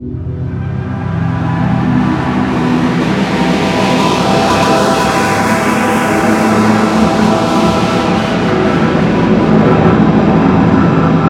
glitchfinambiance.ogg